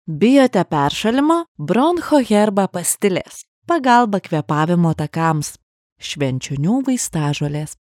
Voice Over Artistes- Lithuanian